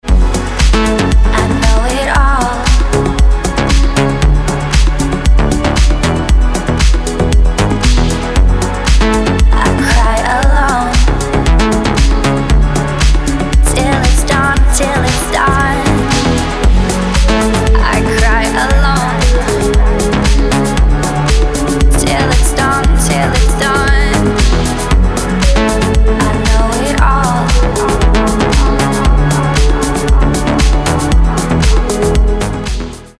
• Качество: 320, Stereo
красивые
женский вокал
dance
Electronic
спокойные
club
house
electro
vocal
Progressive